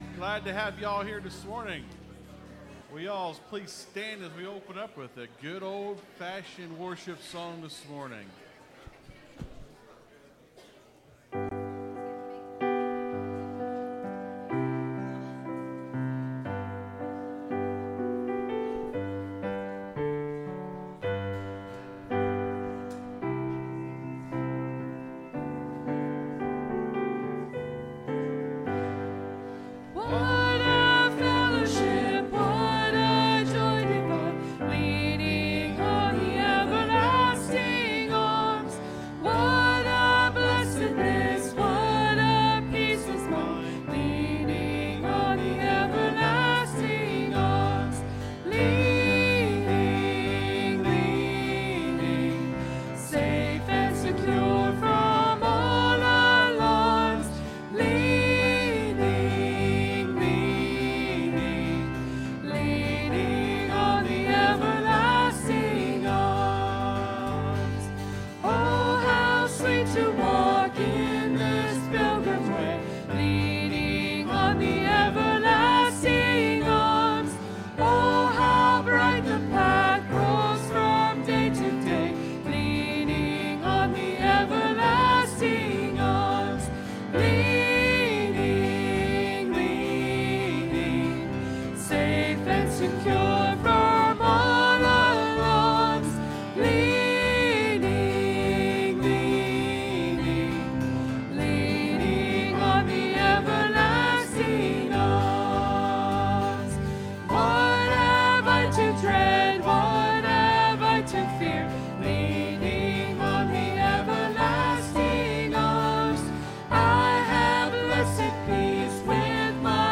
(Sermon starts at 28:10 in the recording).